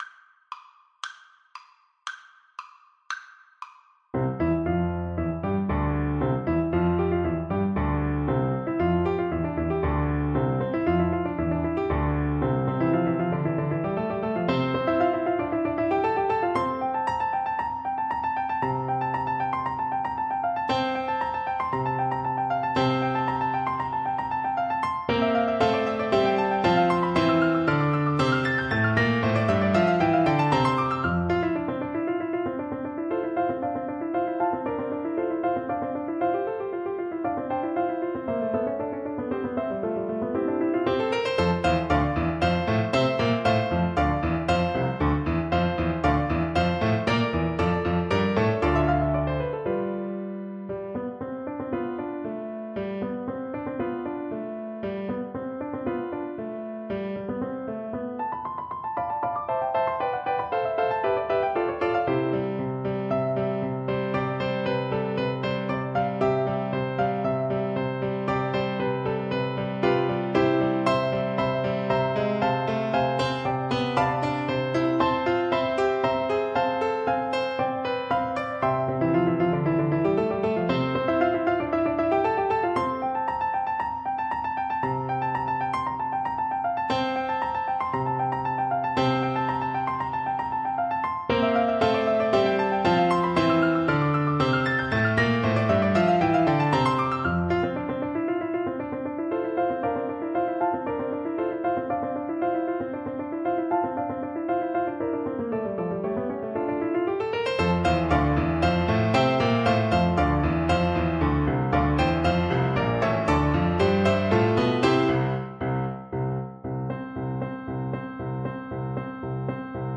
2/4 (View more 2/4 Music)
= 116 Vivace (View more music marked Vivace)
Arrangement for Flute and Piano
Classical (View more Classical Flute Music)